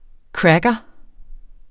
Udtale [ ˈkɹagʌ ]